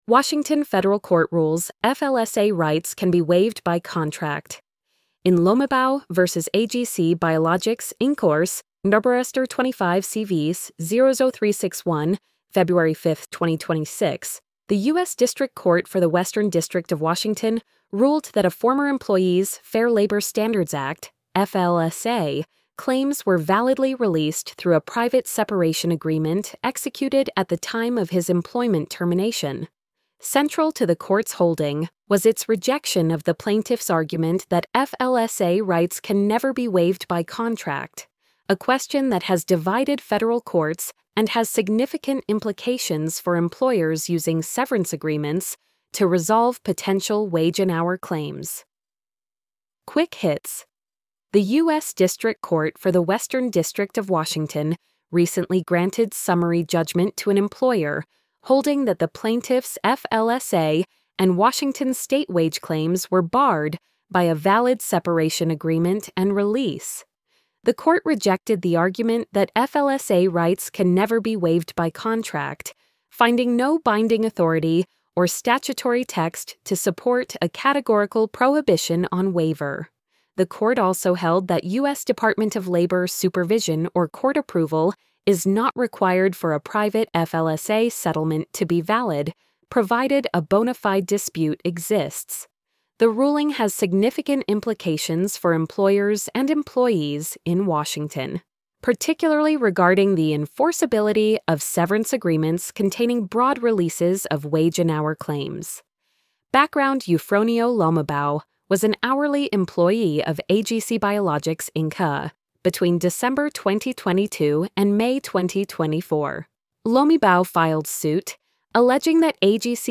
washington-federal-court-rules-flsa-rights-can-be-waived-by-contract-tts.mp3